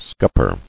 [scup·per]